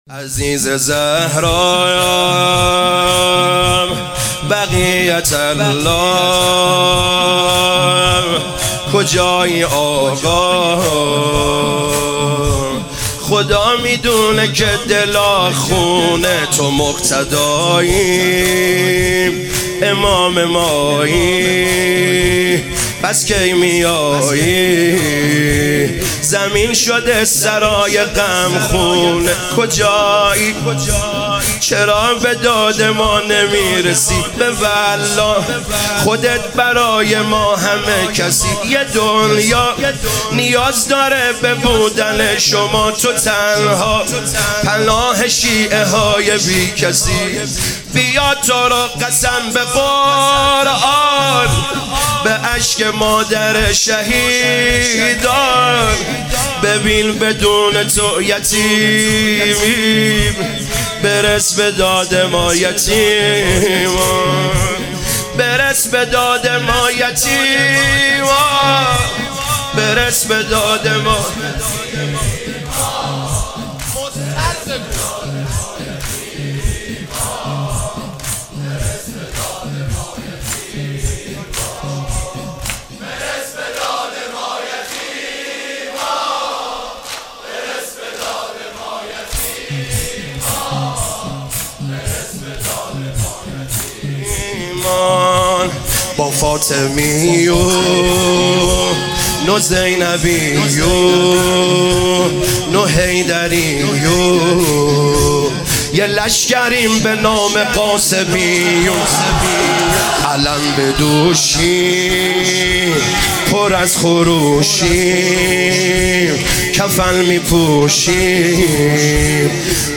شب چهارم فاطمیه 1398
فاطمیه